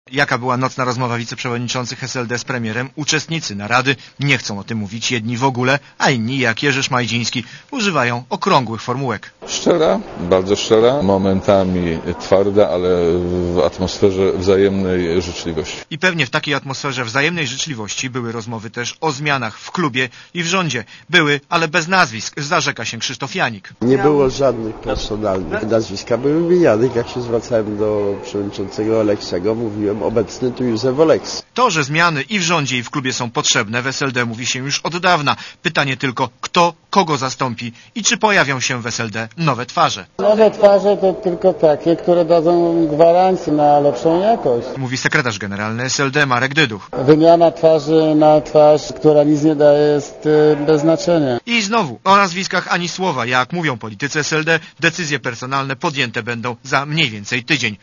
Relacja reportera Radia Zet (228Kb)